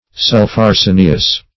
Search Result for " sulpharsenious" : The Collaborative International Dictionary of English v.0.48: Sulpharsenious \Sulph`ar*se"ni*ous\, a. (Chem.)